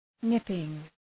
Shkrimi fonetik {‘nıpıŋ}
nipping.mp3